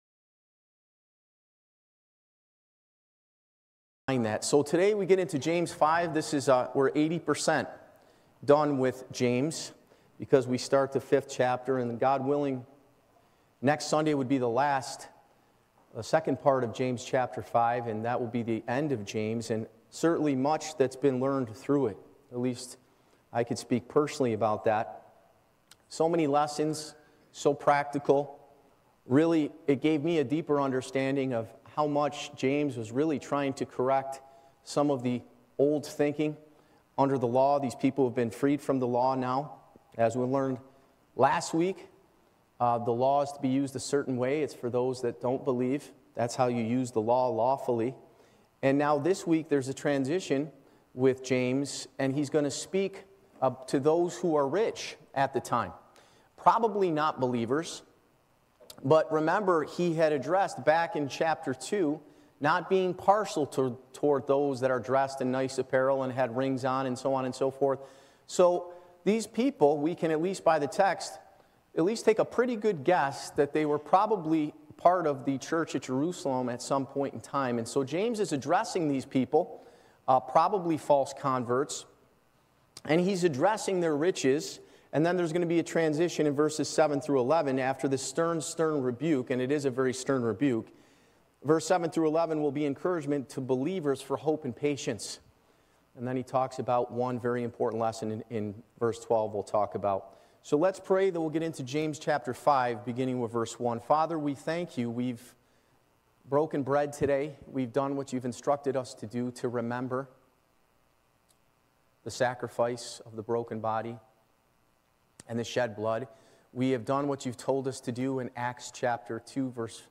Live Recording